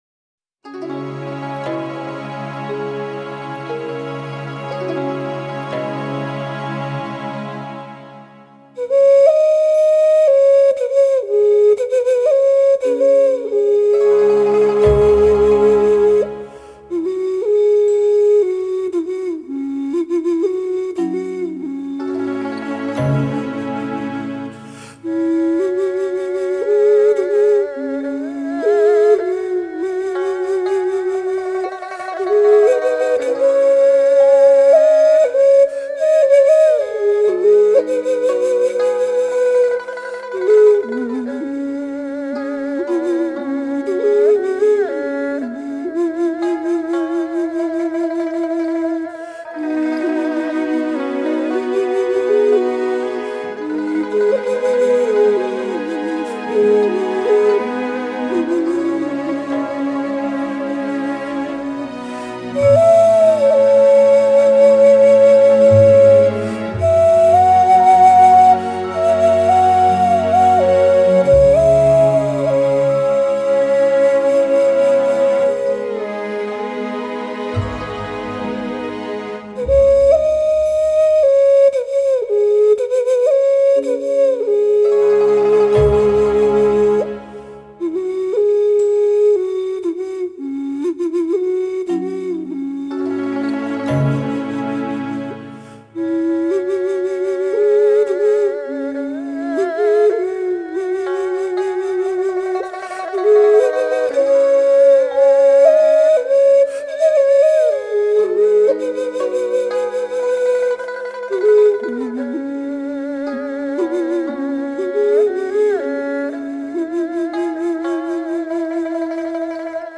乐器：埙